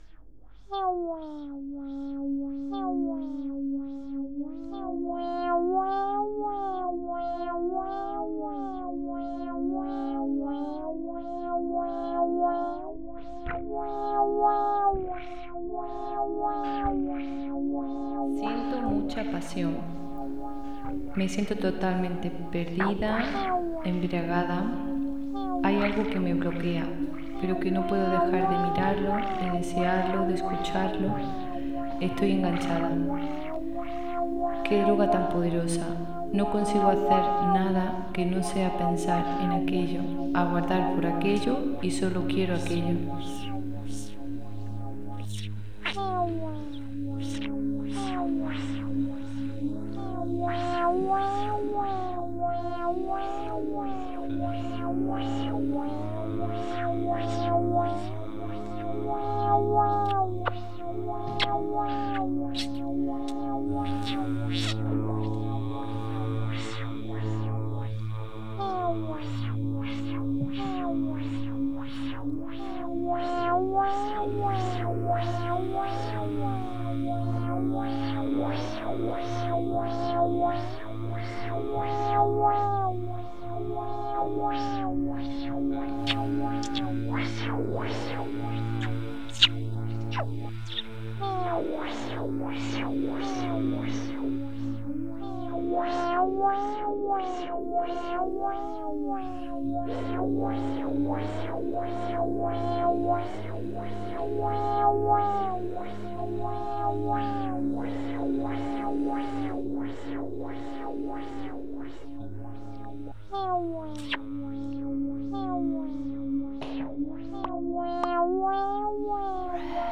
en This audio created by me is a result of a mix of all vocal contributions of the Portuguese collective. These sounds were created from a personal affective memory connected to a song that is relevant for us.
en 8. Sound/music